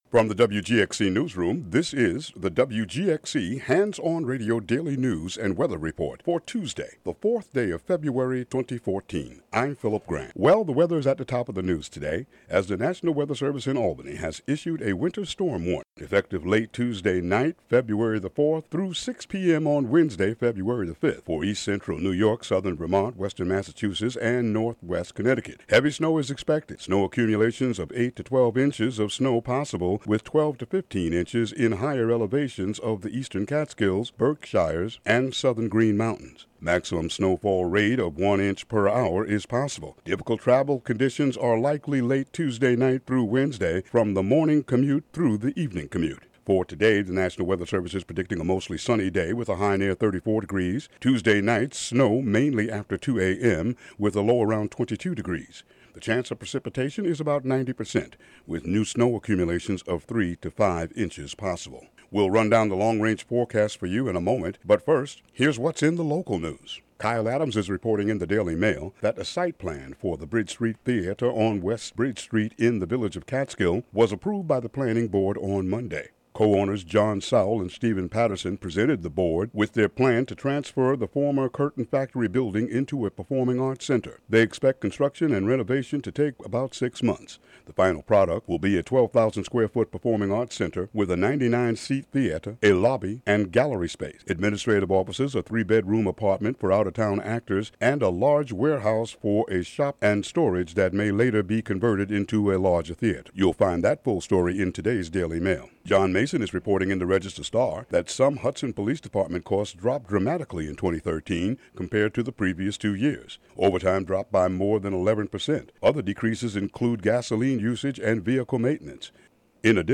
Local news and weather for Tuesday, February 4, 2014.